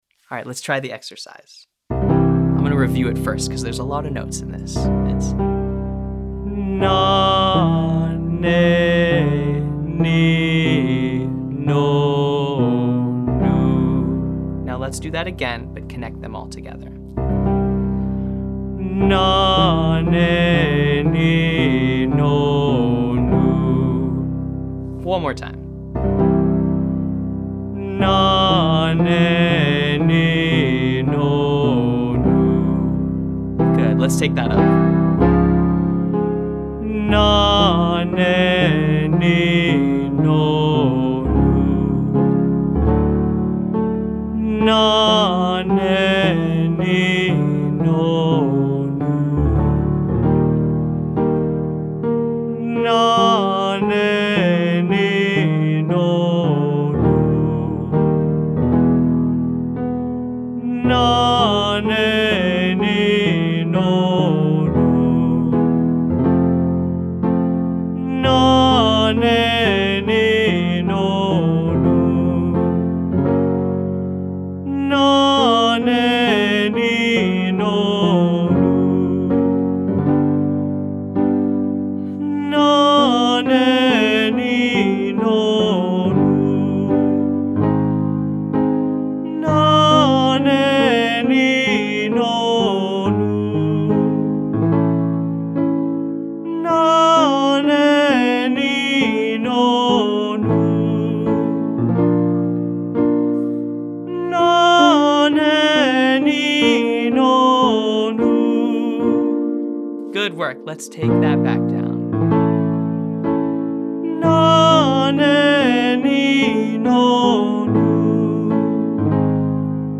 • Nah, Neh, Nee (5,3,4,2,3,1,2,7,1)